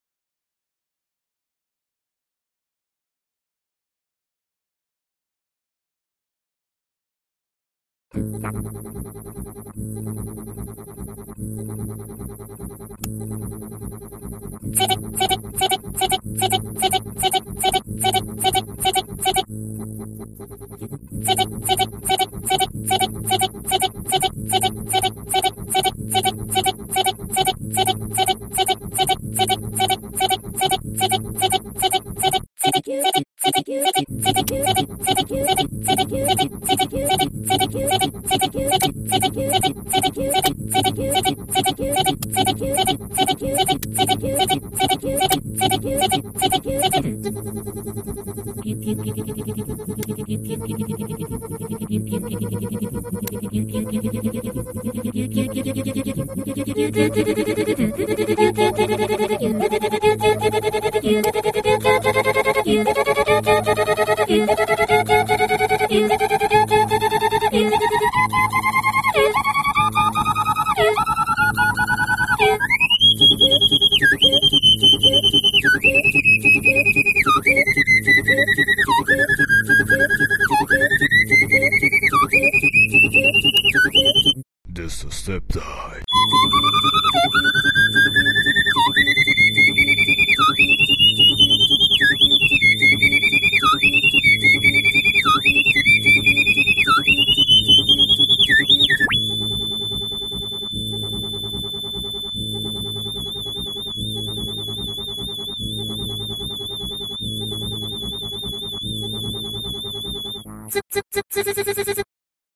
特にシンバル音ミスしたまま放置してるので序盤はうっさいです、サーセンｗｗｗ
ちなみにこれにはどうせ失敗ならと試験的に私の声入れてあります、入れる場所１箇